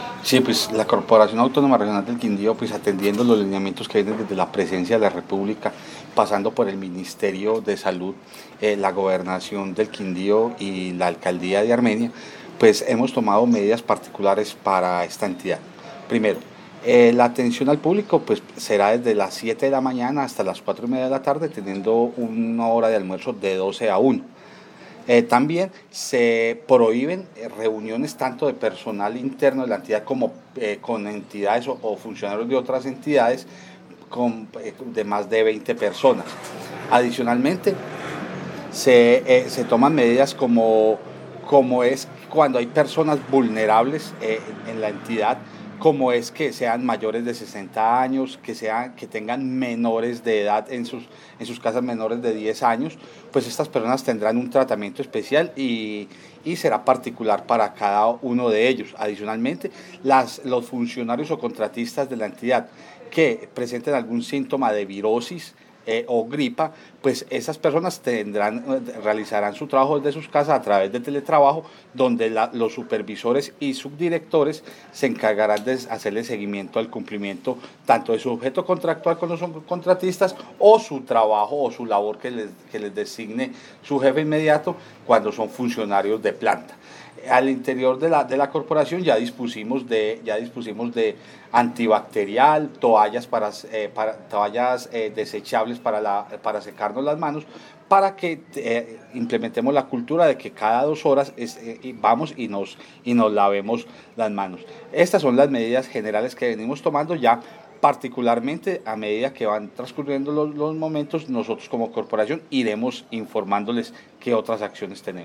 AUDIO JOSÉ MANUEL CORTÉS OROZCO – DIRECTOR GENERAL DE LA CORPORACIÓN AUTÓNOMA REGIONAL DEL QUINDÍO:
AUDIO_DIRECTOR_CRQ_MEDIDAS_CORONAVIRUS.mp3